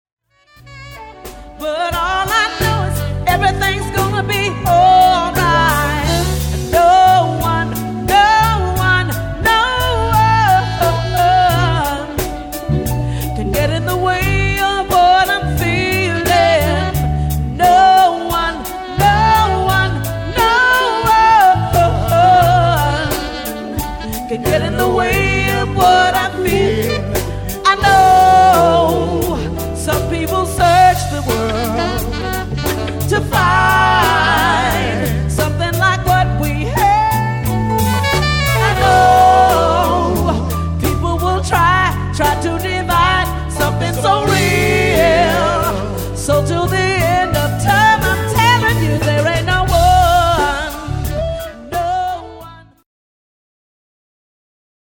Jazz, Standards